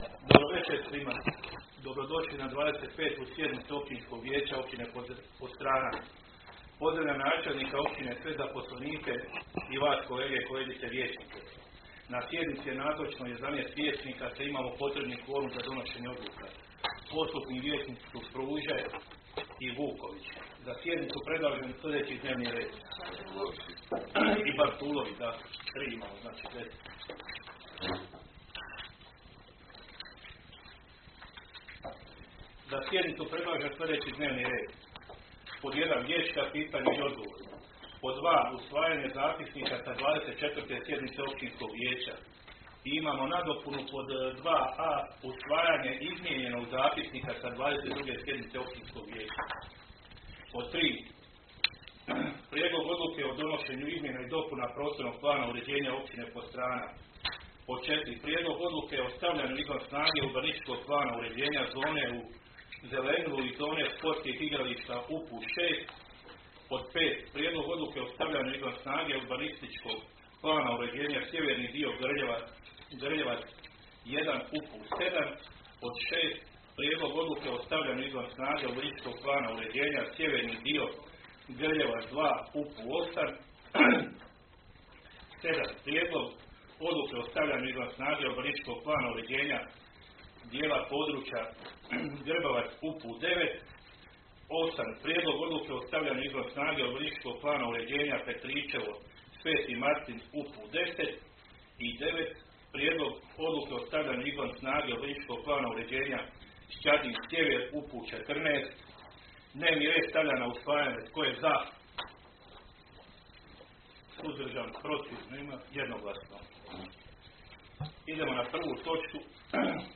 Audio zapis sa 25. sjednice Općinskog vijeća Općine Podstrana, održane dana 01. veljače (četvrtak) 2024. godine u 19,00 sati u vijećnici Općine Podstrana